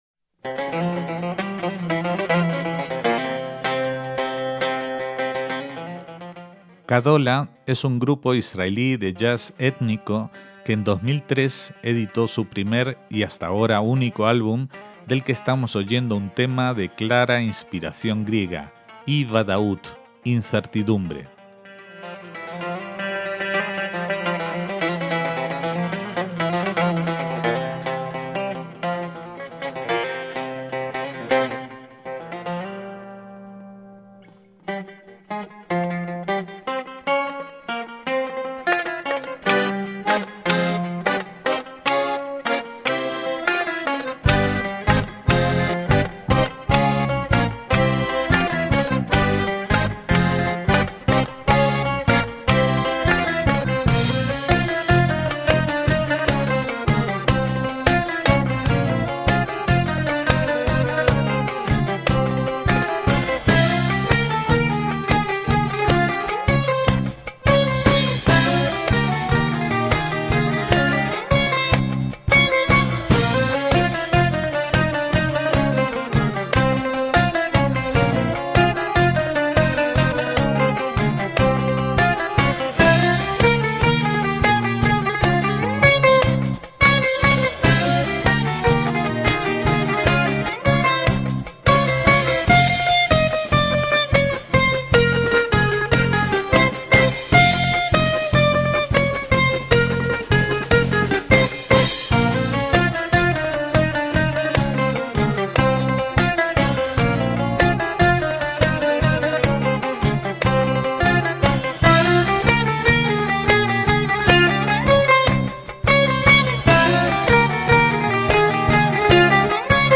guitarras y bajos
piano y acordeón
percusiones